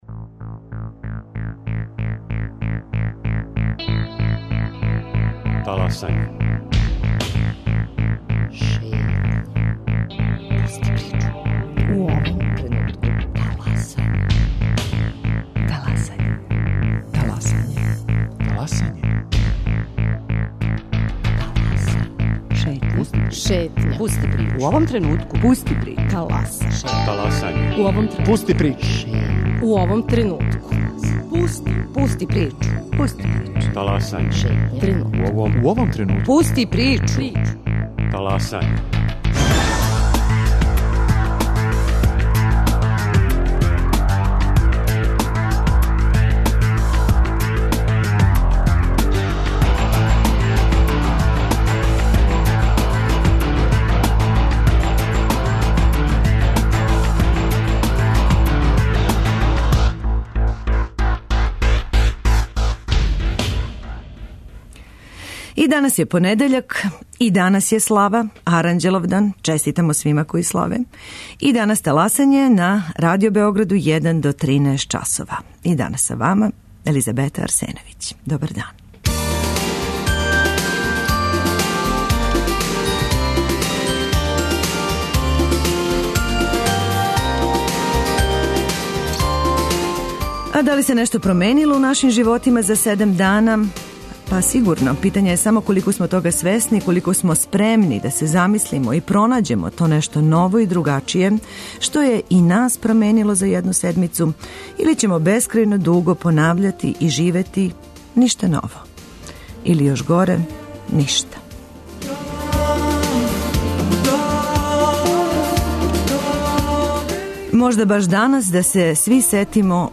А и овог понедељка биће места и времена за слушаоце и њихова укључења. Питаћемо вас - може ли Српска академија наука и уметности да помогне Србији у тешким временима, која већ дуго живимо, и како би по вама та помоћ требало да изгледа?